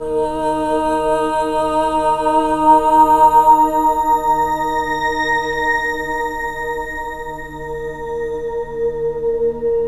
Index of /90_sSampleCDs/Zero-G - Total Drum Bass/Instruments - 1/track12 (Pads)